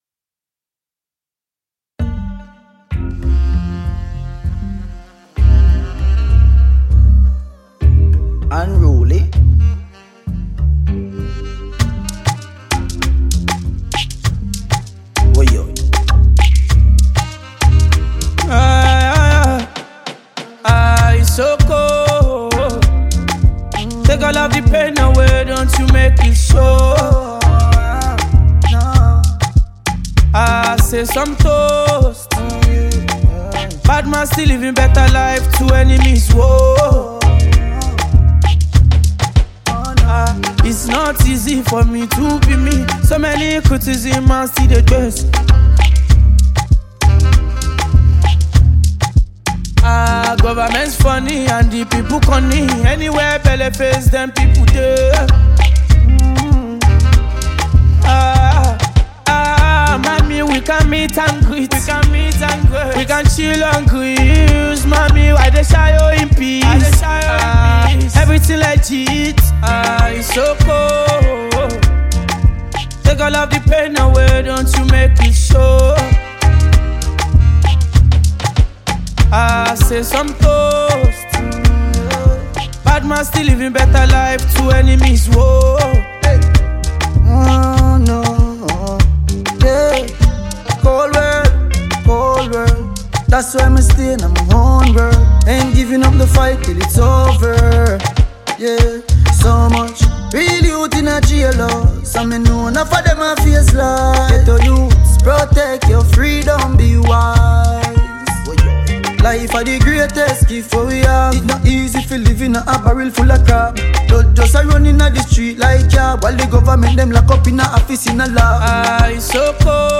a radio and club-friendly Jam